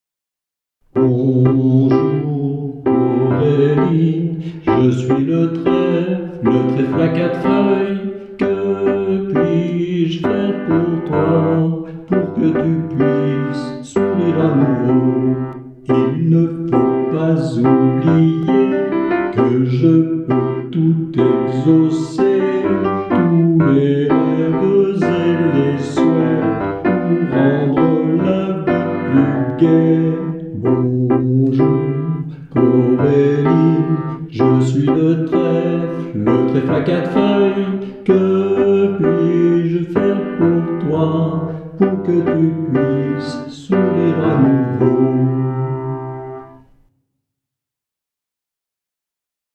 un conte musical pour enfants